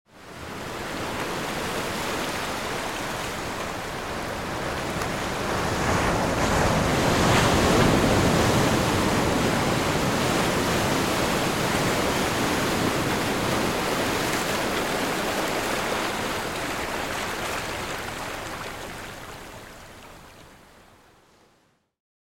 جلوه های صوتی
دانلود صدای موج 1 از ساعد نیوز با لینک مستقیم و کیفیت بالا
برچسب: دانلود آهنگ های افکت صوتی طبیعت و محیط دانلود آلبوم صدای موج آب دریا از افکت صوتی طبیعت و محیط